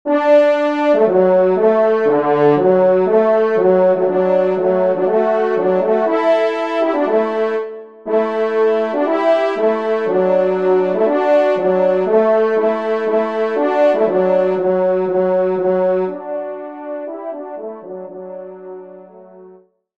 Genre :  Musique Religieuse pour Trois Trompes ou Cors
Pupitre 2°Trompe